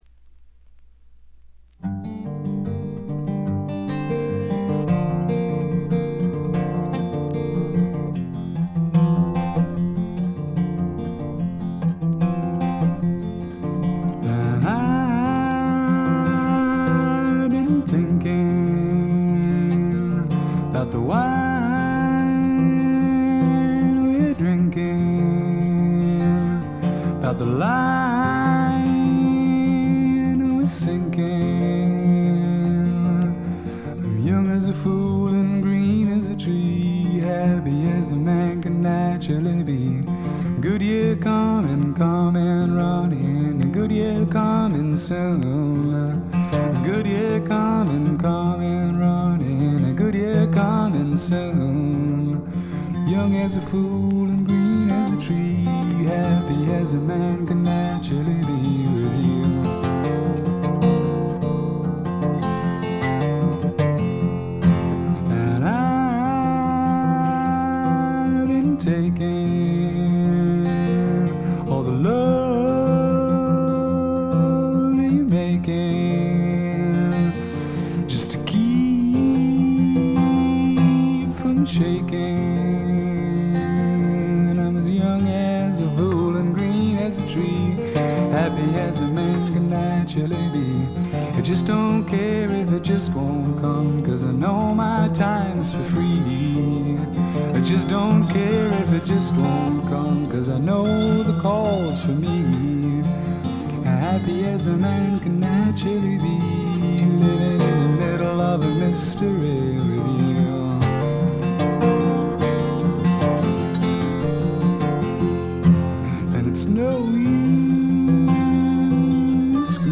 ホーム > レコード：英国 SSW / フォークロック
試聴曲は現品からの取り込み音源です。